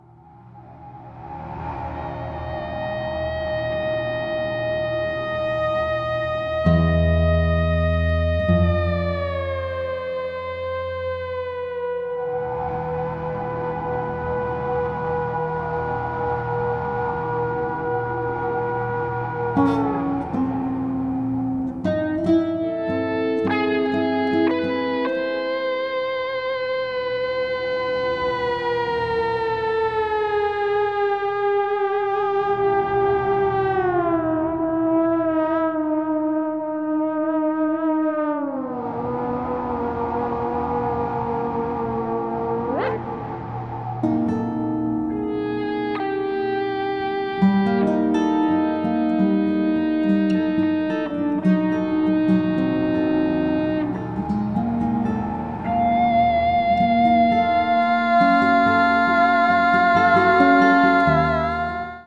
Electric guitar, Soundscapes, Live performance electronics